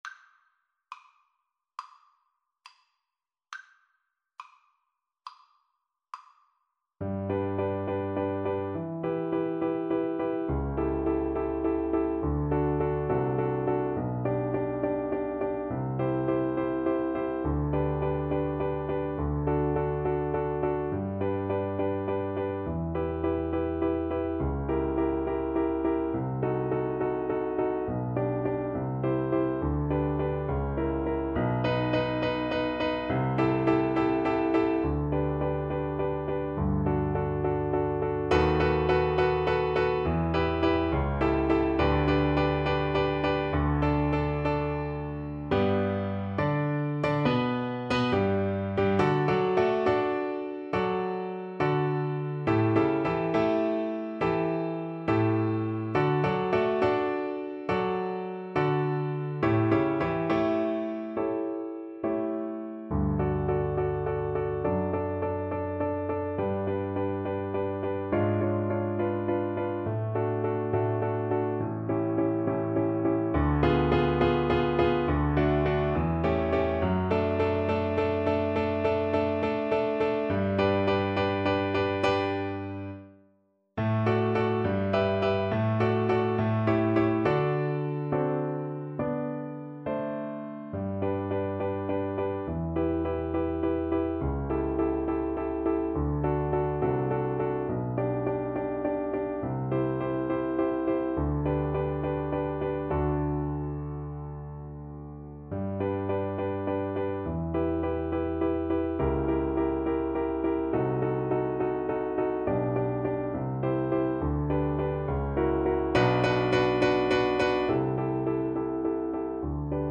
4/4 (View more 4/4 Music)
Classical (View more Classical Cello Music)